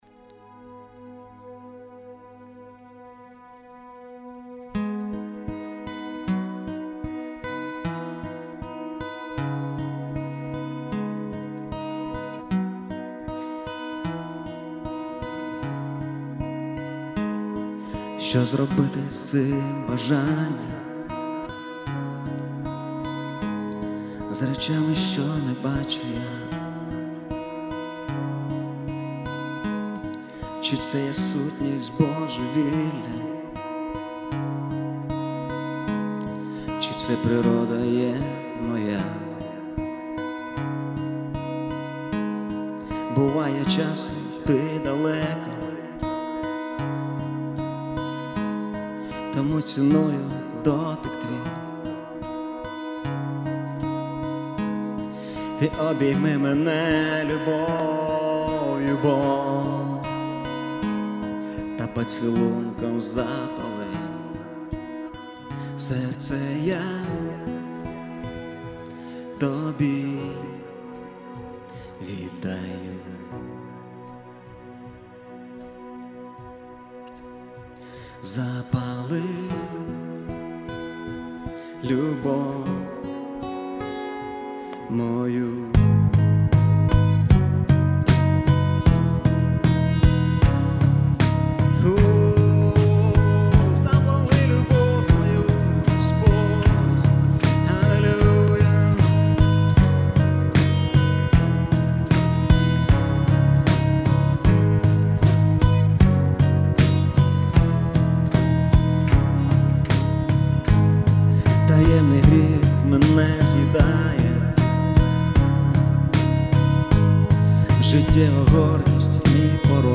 171 просмотр 107 прослушиваний 7 скачиваний BPM: 154